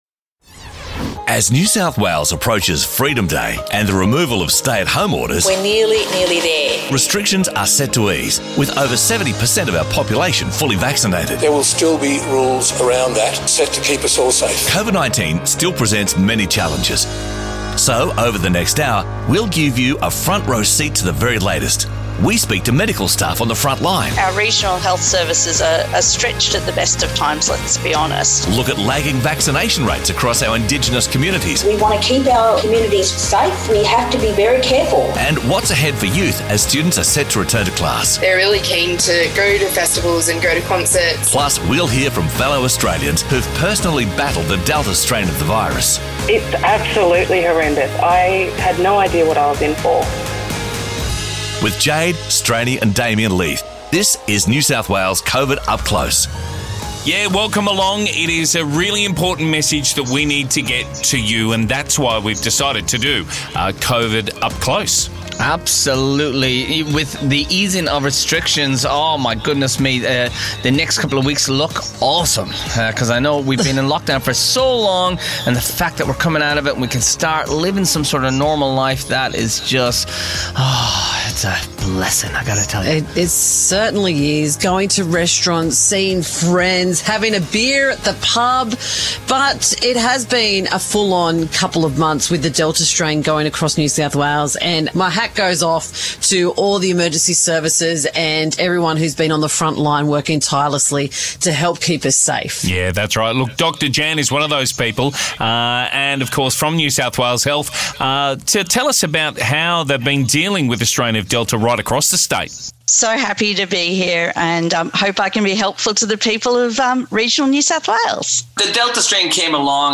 This COVID Up Close Special gives you a front-row seat into how our hospital staff are dealing with patients in ICU, plus we talk to patients who've recently battled the delta strain of the virus. You'll also hear about challenges ahead for our Indigenous communities lagging behind on vaccination rates, and insights into what's coming for NSW youth as students prepare to return to class October 25.